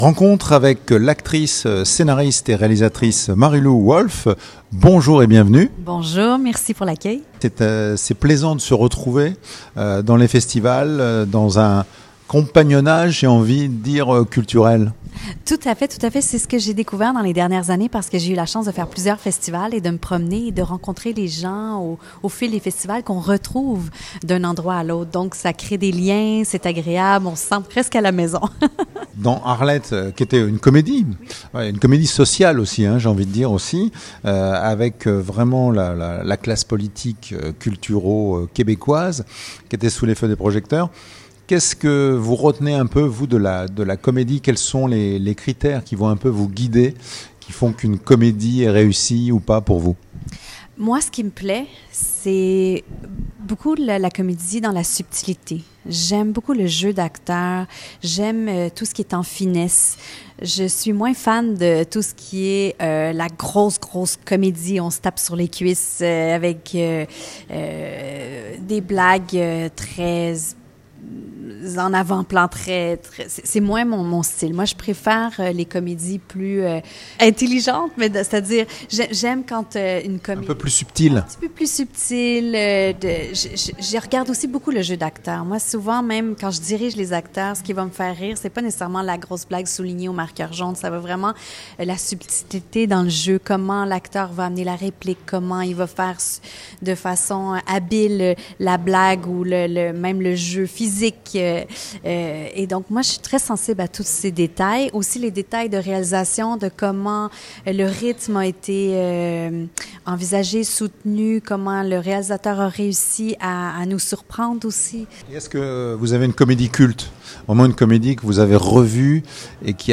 Lors de notre rencontre, Mariloup Wolfe a exprimé l’importance de son rôle en tant que jurée, marquant un point d’honneur à évaluer chaque film avec l’attention qu’il mérite.